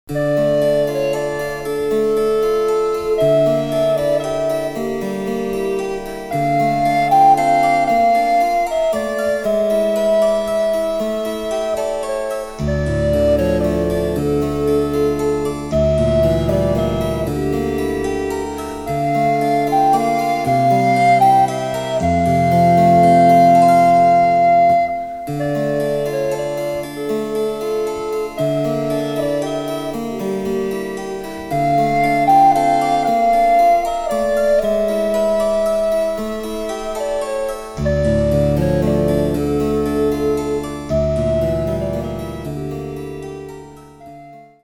デジタルサンプリング音源使用
・伴奏はモダンピッチのみ。